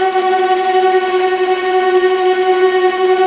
Index of /90_sSampleCDs/Roland - String Master Series/STR_Vlas Bow FX/STR_Vas Tremolo